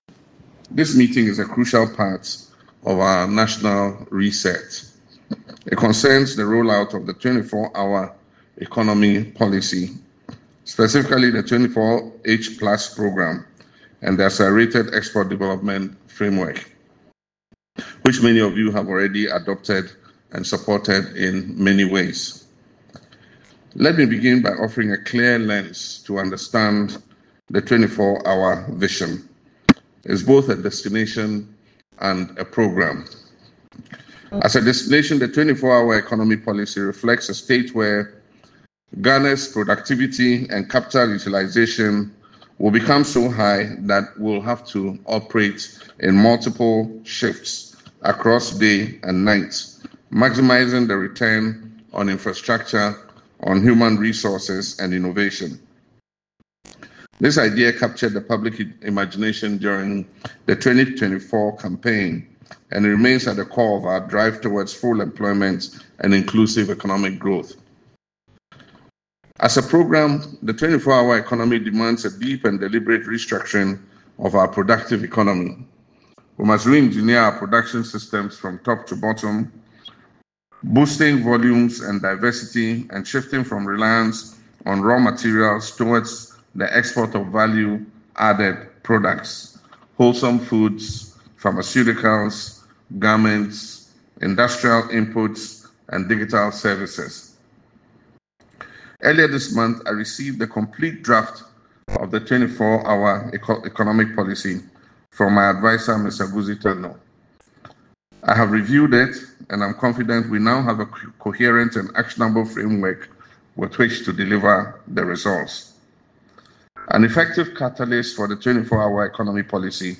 He revealed this during a multi-sectoral engagement at the Jubilee House, where he addressed business leaders, trade associations, and stakeholders from across the country.